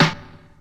• 2000s Small Reverb Hip-Hop Snare One Shot D# Key 102.wav
Royality free snare one shot tuned to the D# note. Loudest frequency: 1686Hz